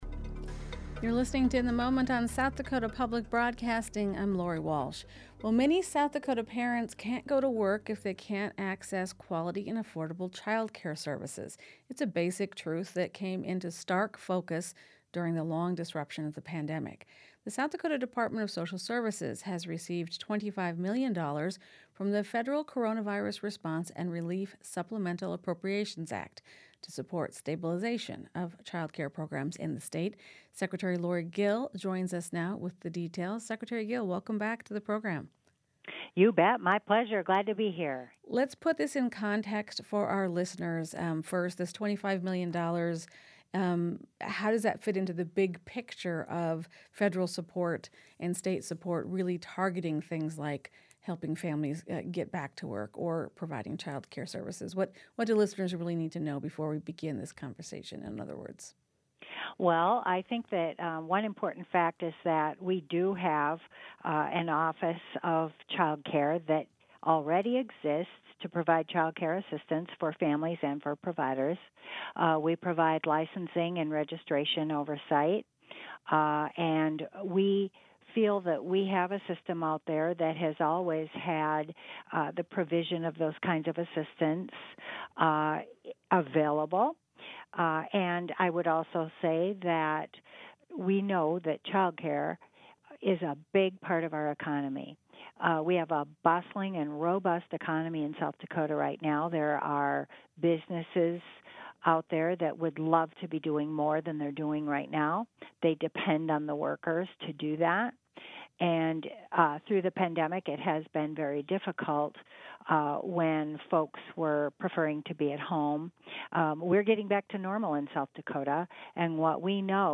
This story comes from a recent interview on SDPB's weekday radio program, " In the Moment ." Listen to the full interview below.